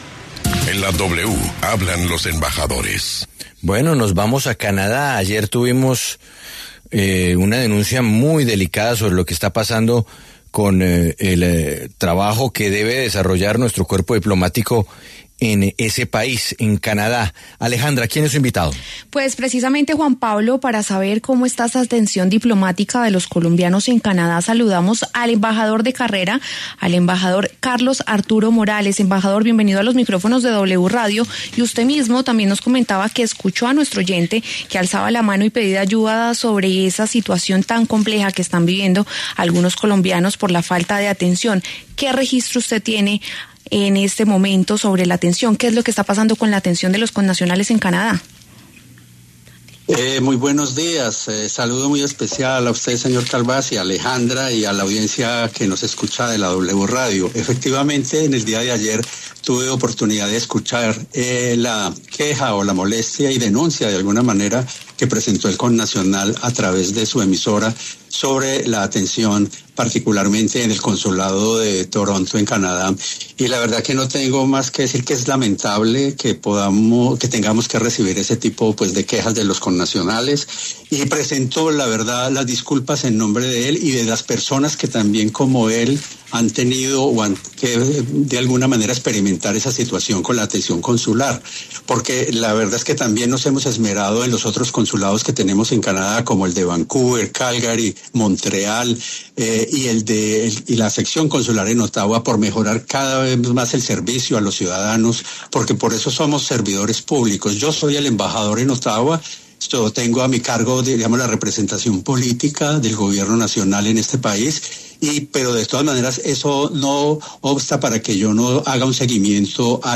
Luego de las denuncias de colombianos en Canadá que aseguran no recibir una atención adecuada en el consulado de Toronto, el embajador de carrera en ese país, Carlos Arturo Morales, ofreció disculpas en La W por la situación y afirmó que están tomando medidas al respecto.